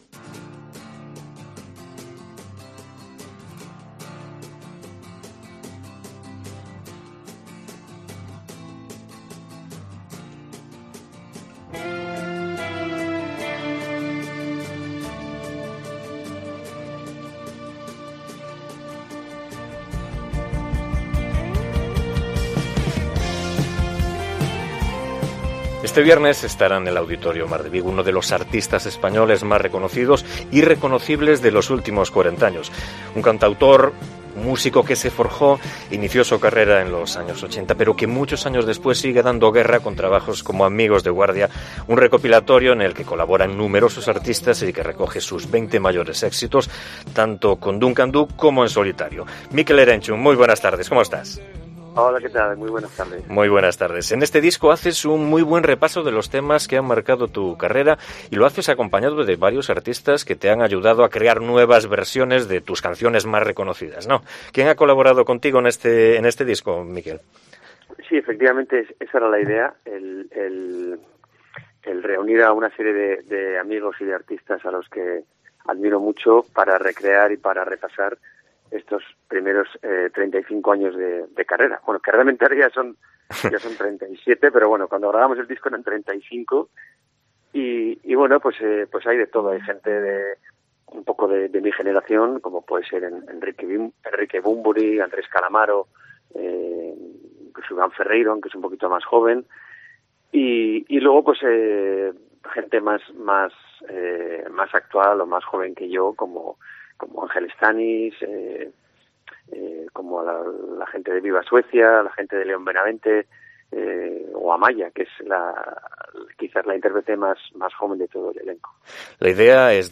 Entrevistamos a Mikel Erentxun, que presenta en Vigo su disco "Amigos de guardia"